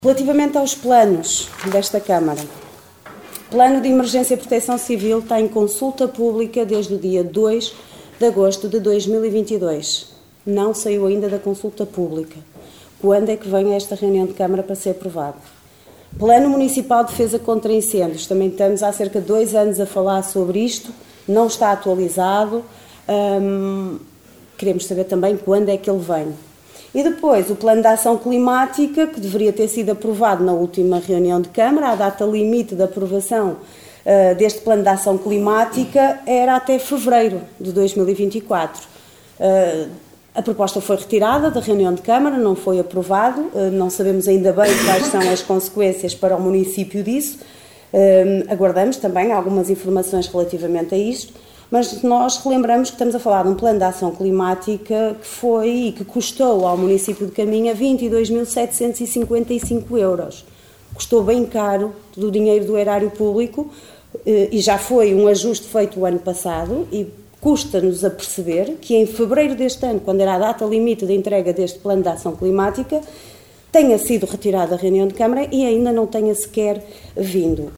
Estas foram as questões deixadas pela vereadora da Coligação O Concelho em Primeiro (OCP) Liliana Silva no período antes da ordem do dia, referentes a estes importantes documentos que a Câmara já deveria ter aprovados e implementados mas não tem.
reuniao-camara-6-mar-planos-liliana-silva-001.mp3